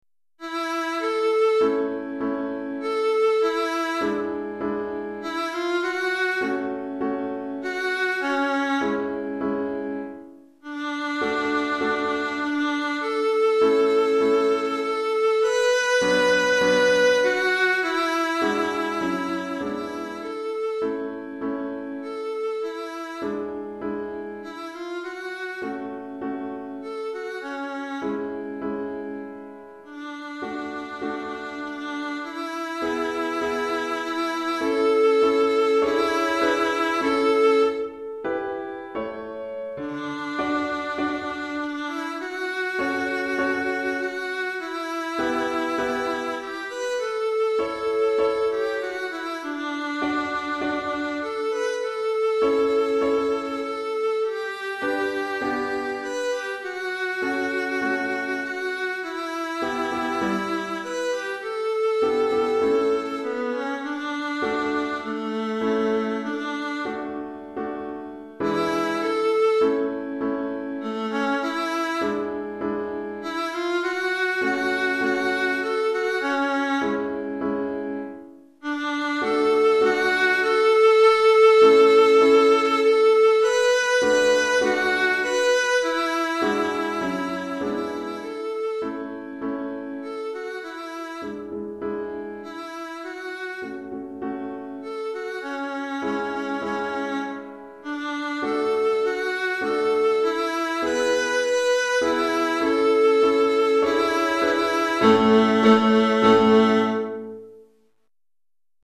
Pour alto et piano DEGRE CYCLE 1